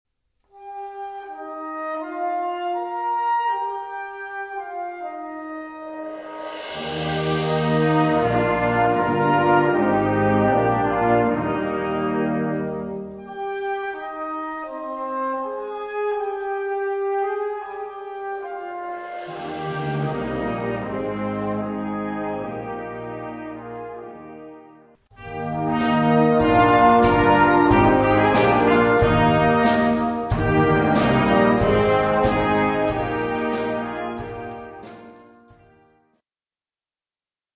Categorie Harmonie/Fanfare/Brass-orkest
Subcategorie Concertmuziek
Instrumentatie/info 4part; Perc (slaginstrument)